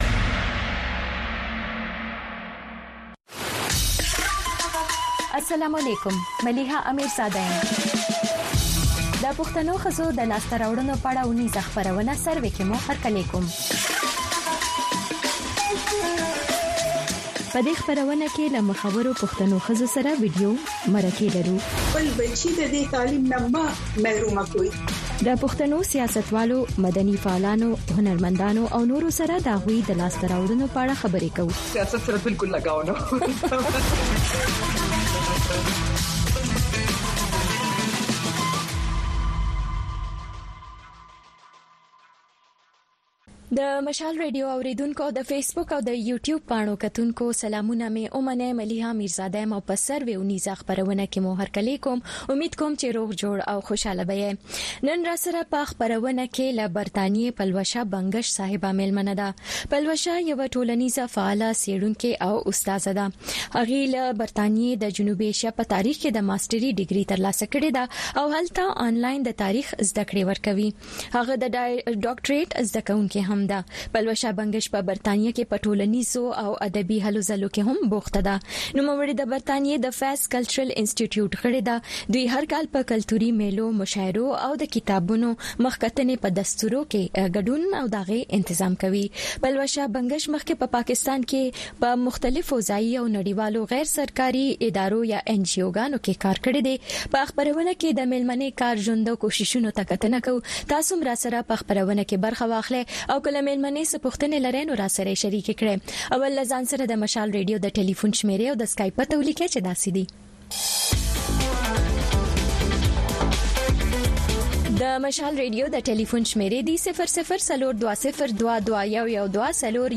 خبرونه
د وی او اې ډيوه راډيو سهرنې خبرونه چالان کړئ اؤ د ورځې دمهمو تازه خبرونو سرليکونه واورئ.